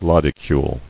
[lod·i·cule]